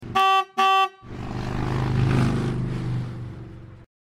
motorcycle-horn.mp3